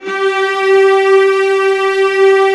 VIOLAS AN4-R.wav